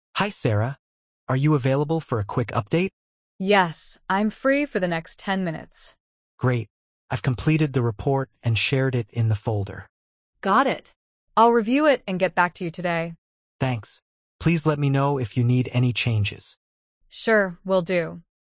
🤝 Two colleagues quickly check in on a task.